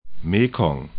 'me:kɔŋ